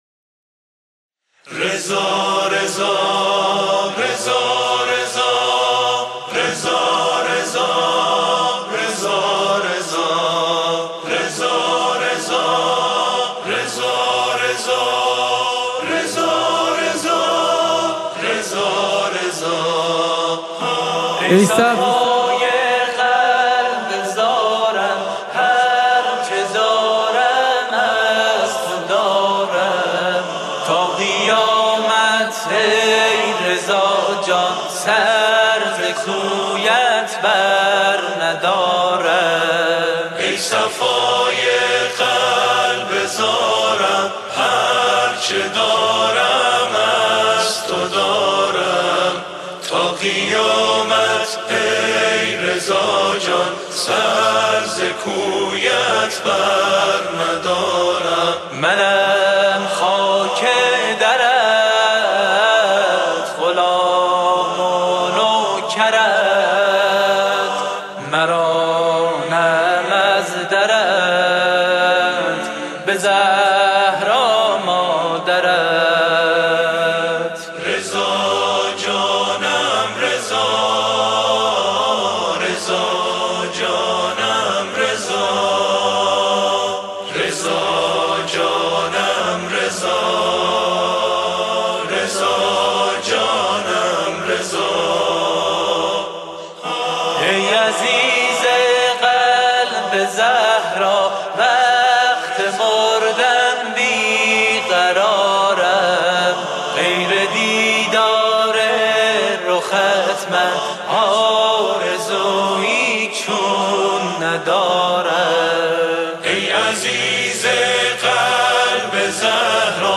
همخوانی | ای صفای قلب زارم